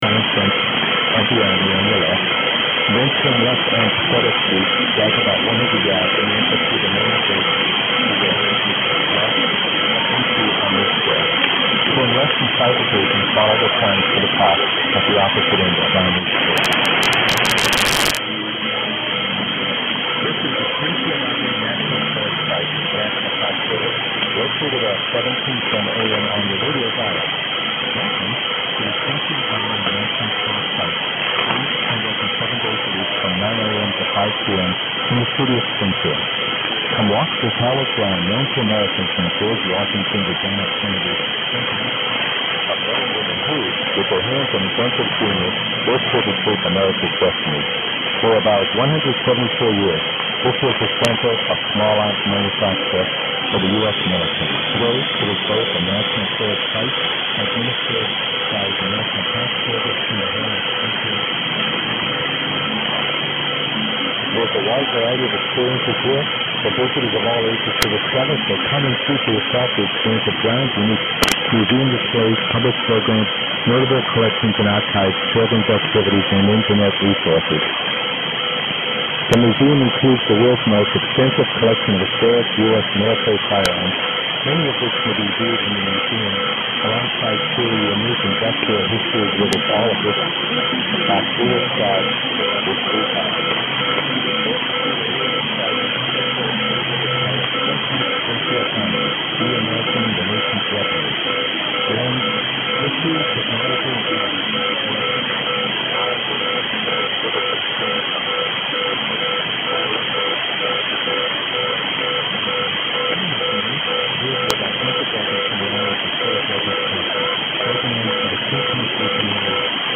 But I do know that after chasing this for a week, I finally heard ids from Springfield Armoury / Armory (American spelling).
Around 0500 is obviously the peak of the propagation at this latitude at this time.
140411_0500_1710_springfield_armory.mp3 (Long clip with really good peaks)